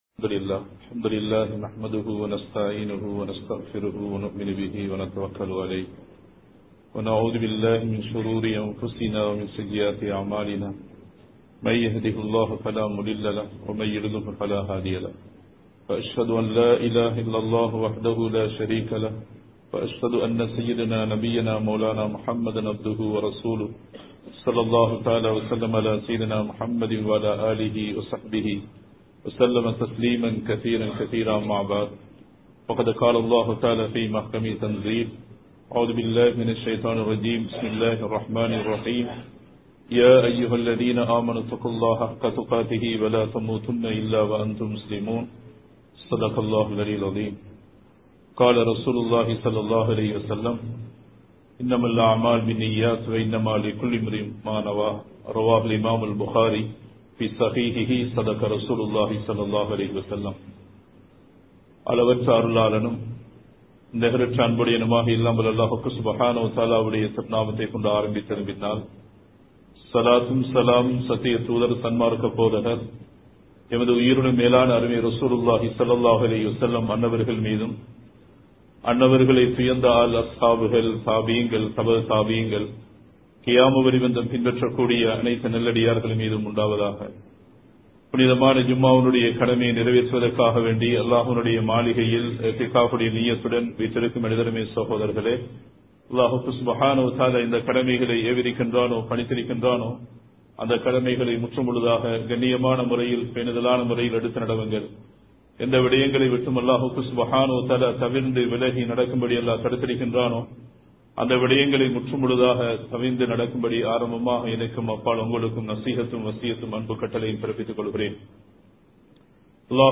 பொறுமையின் சிறப்பு | Audio Bayans | All Ceylon Muslim Youth Community | Addalaichenai
Kirulapana Thaqwa Jumua Masjith